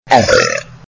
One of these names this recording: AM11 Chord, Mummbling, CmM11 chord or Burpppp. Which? Burpppp